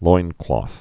(loinklôth, -klŏth)